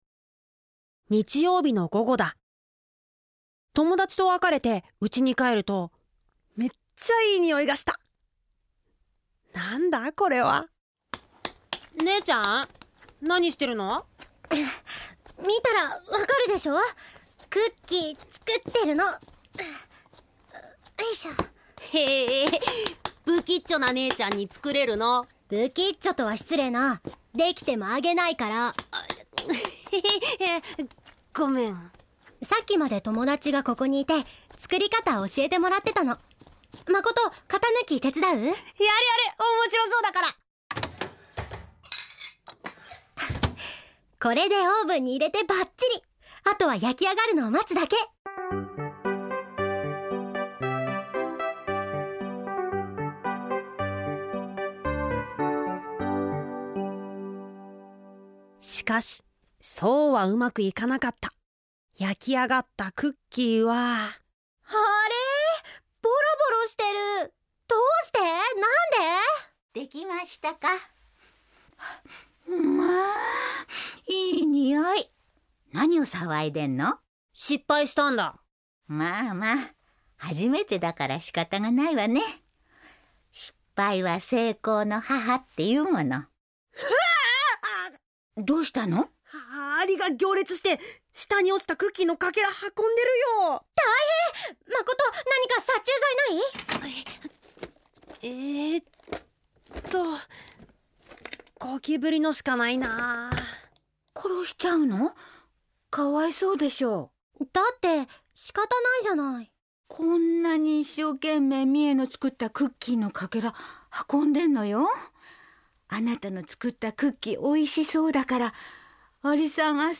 ●ラジオドラマ「鈴木家の教訓」